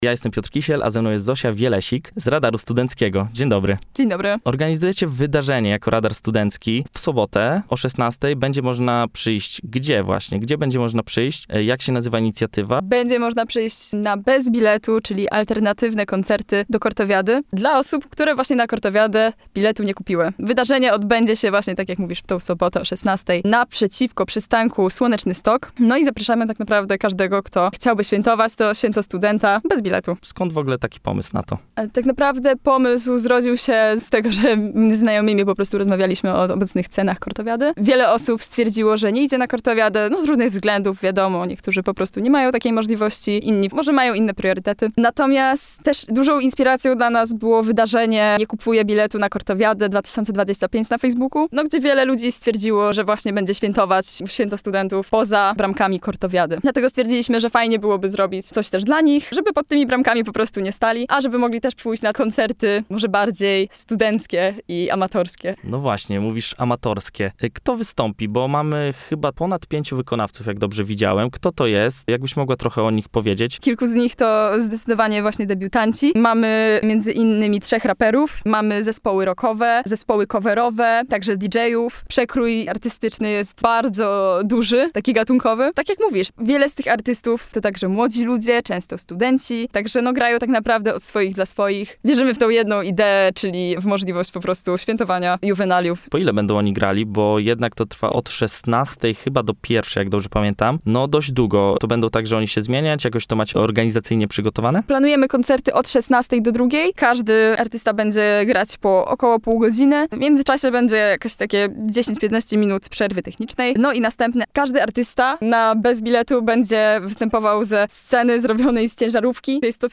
– mówiła w studiu Radia UWM FM.